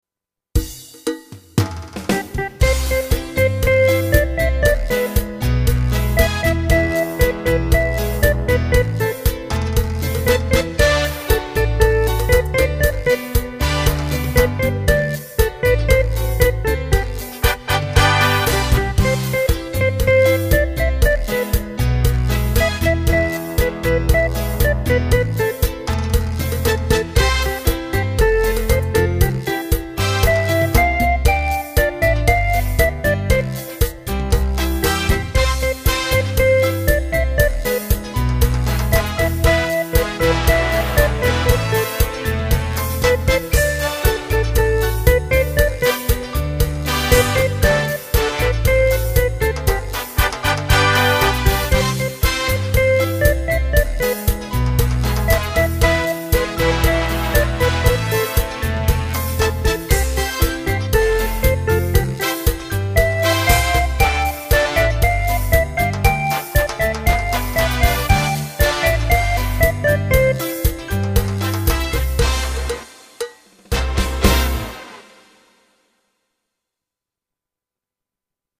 Arrangiamenti didattici di brani d'autore